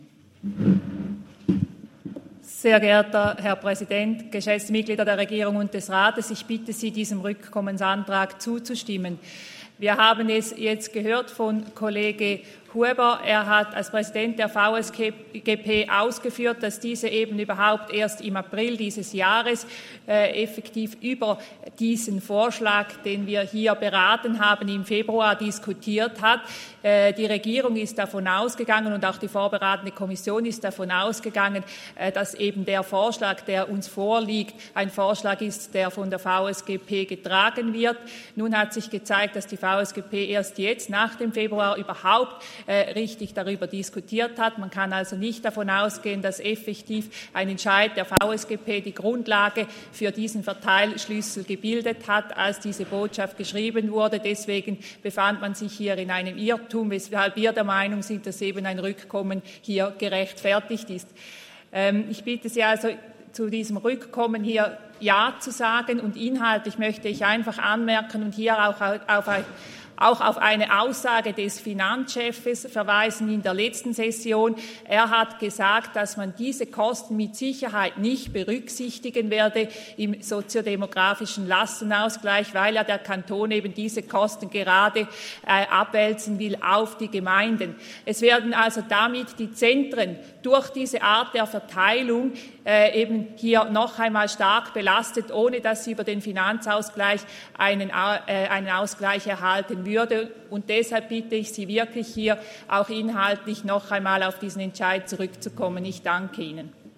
Session des Kantonsrates vom 12. bis 14. Juni 2023, Sommersession